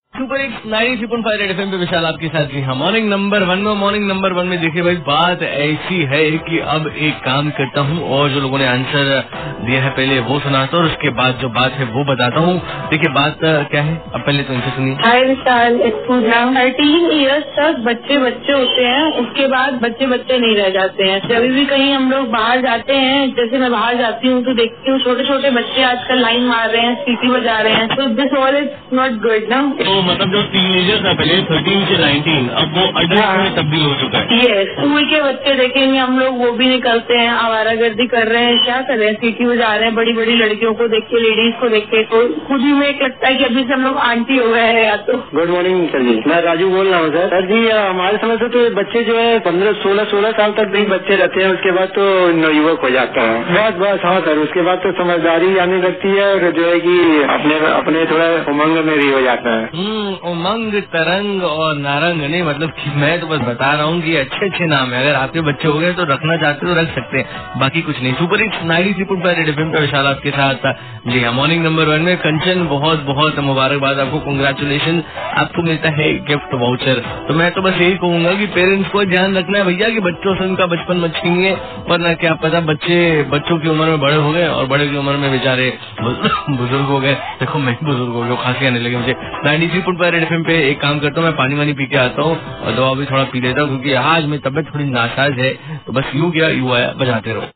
caller with rj